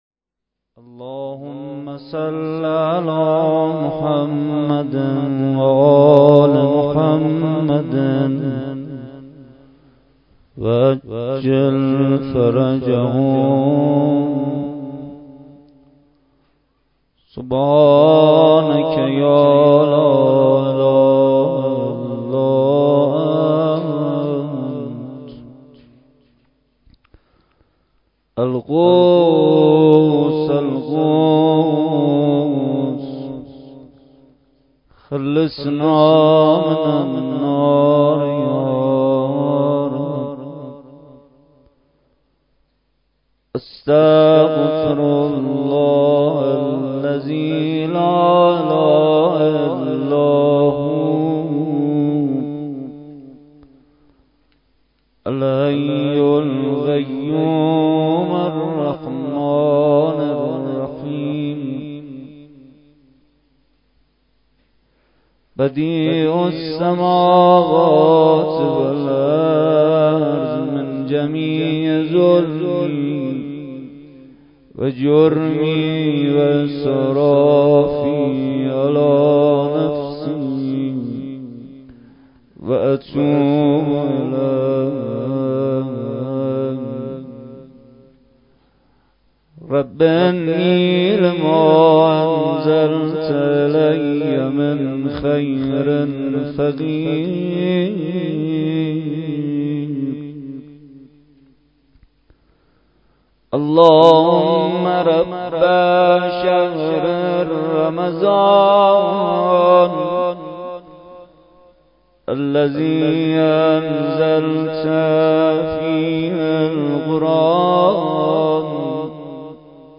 شب دوازدهم ماه رمضان با مداحی کربلایی محمدحسین پویانفر در ولنجک – بلوار دانشجو – کهف الشهداء برگزار گردید.